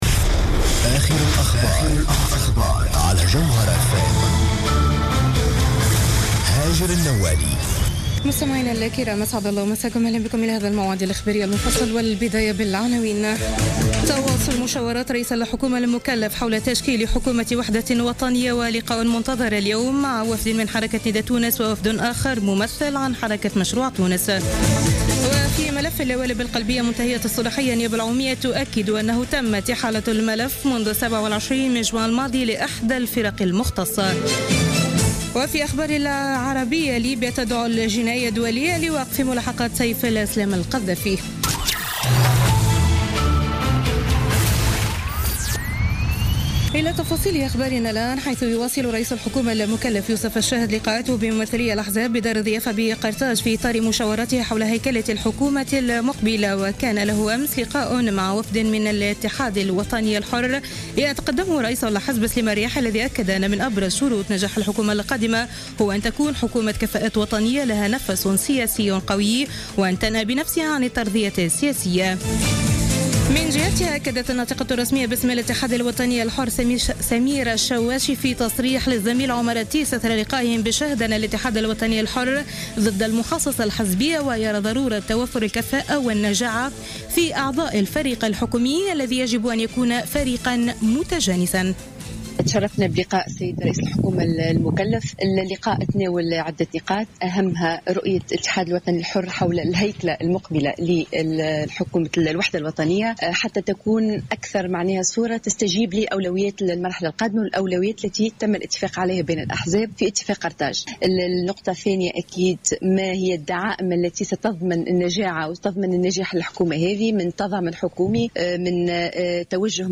نشرة أخبار منتصف الليل ليوم الإثنين 8 أوت 2016